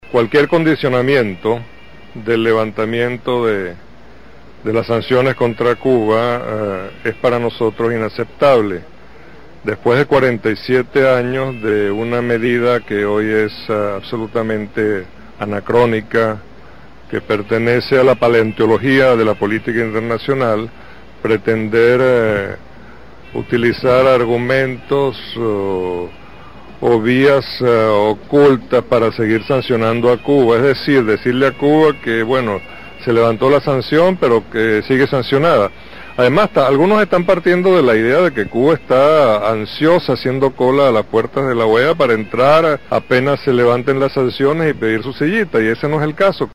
Roy Chaderton, embajador de Venezuela ante la OEA se refirió a la postura de Cuba ante esta instancia